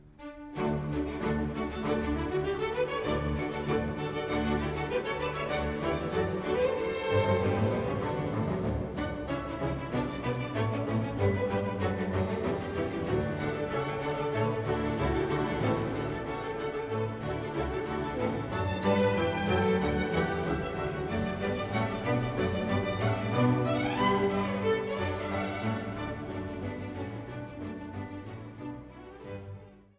Passionskantate für Soli, Chor und Orchester
Recitativo (Bass) „Jerusalem voll Mordlust ruft mit wildem Thon“